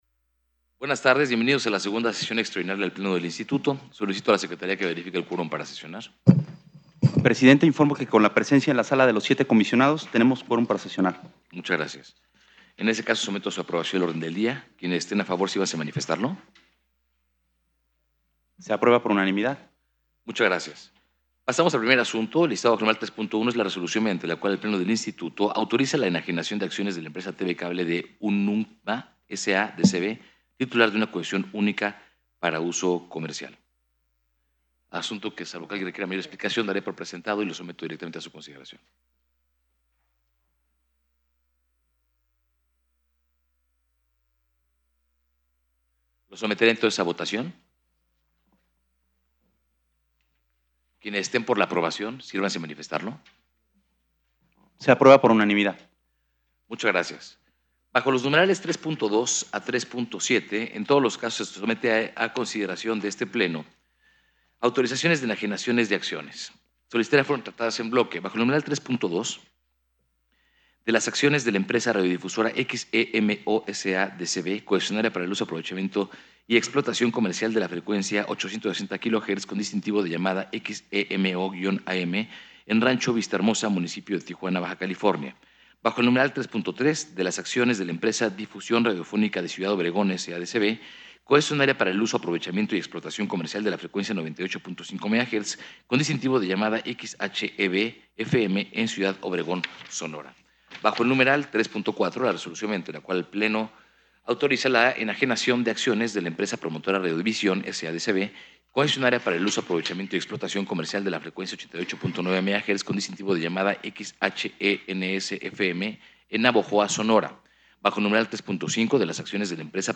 Audio de la sesión